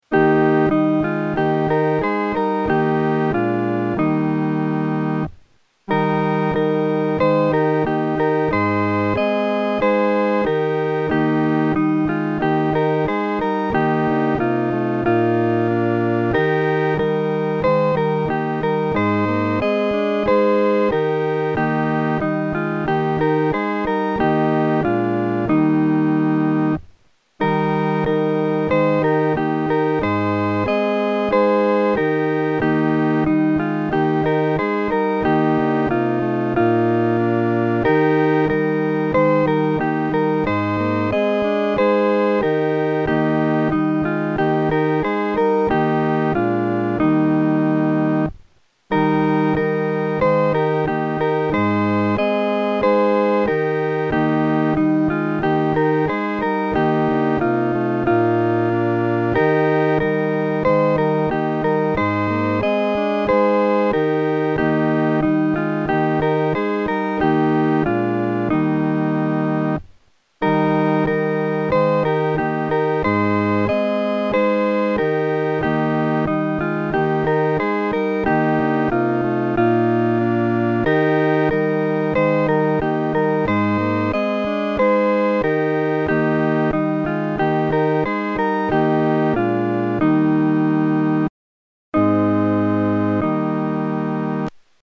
伴奏
四声